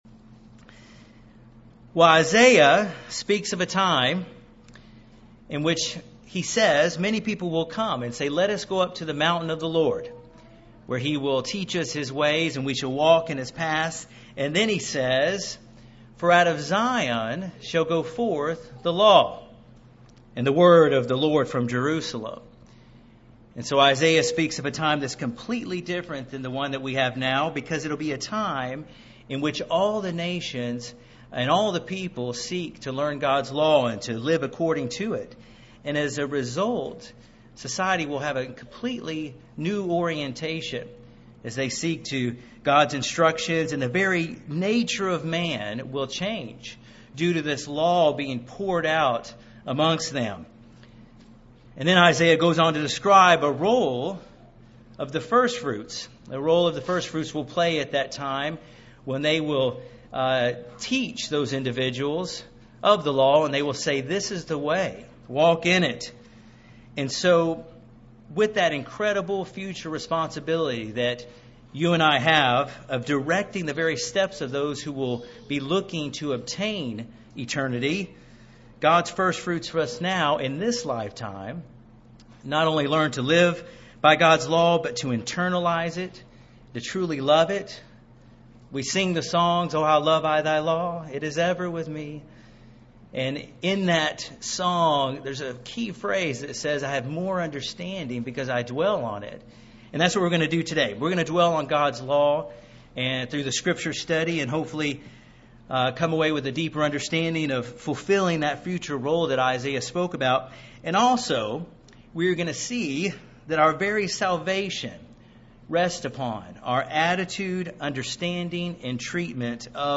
This sermon looks at the Law of God in the New Testament and compares it to another law mentioned in the New Testament.